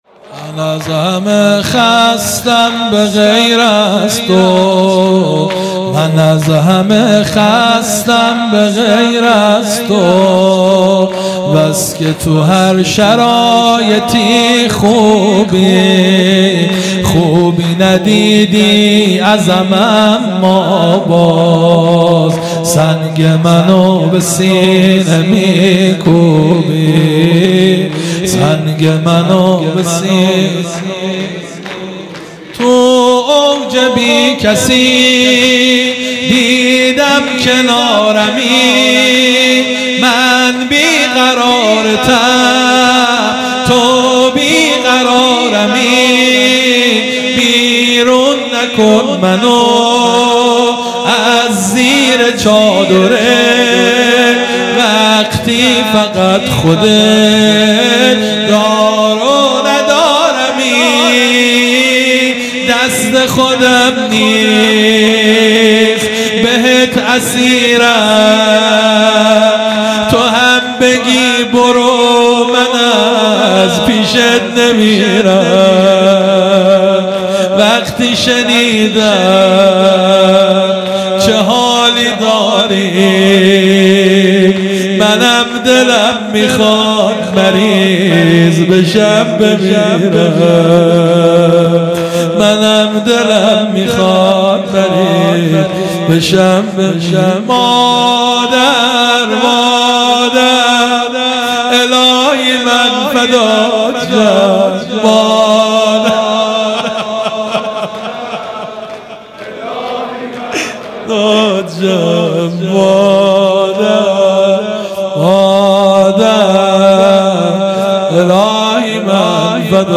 شور | من از همه خستم مداح